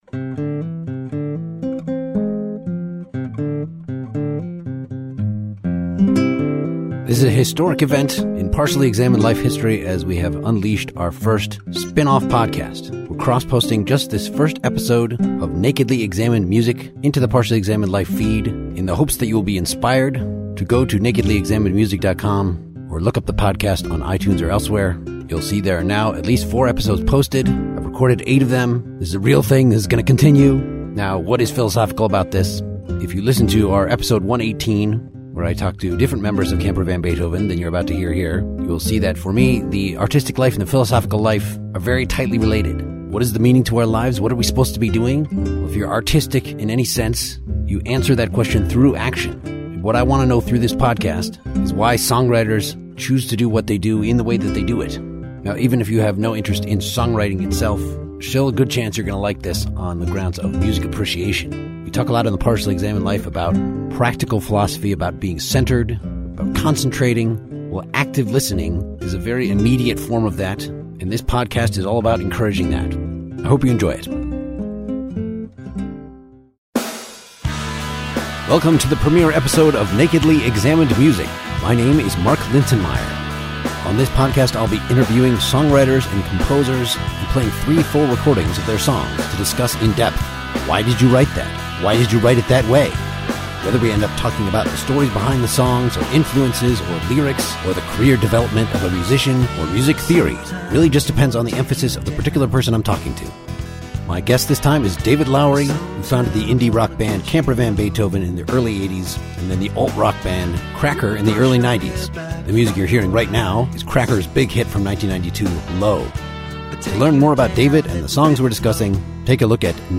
Four episodes are now posted; this cross-post of our pilot features David Lowery of Camper van Beethoven and Cracker talking through three of his songs.